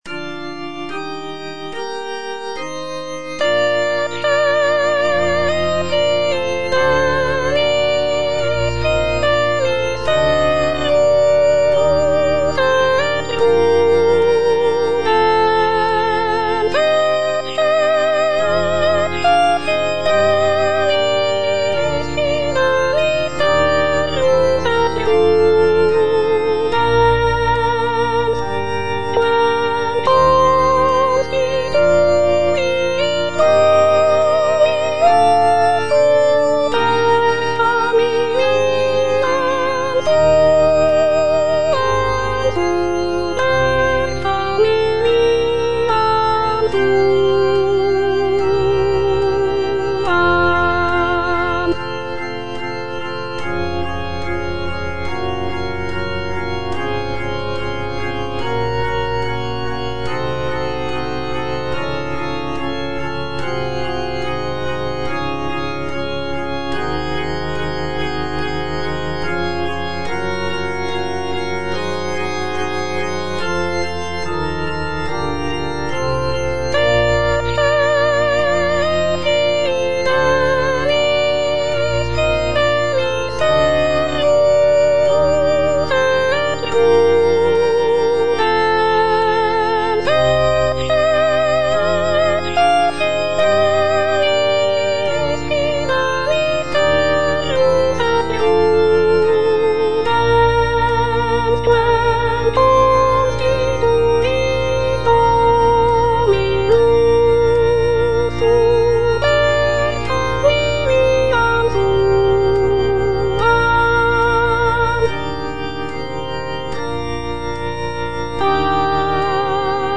G. FAURÉ - ECCE FIDELIS SERVUS Soprano (Voice with metronome) Ads stop:
"Ecce fidelis servus" is a sacred choral work composed by Gabriel Fauré in 1896. The piece is written for four-part mixed choir and organ, and is based on a biblical text from the Book of Matthew. The work is characterized by Fauré's trademark lyrical melodies and lush harmonies, creating a serene and contemplative atmosphere.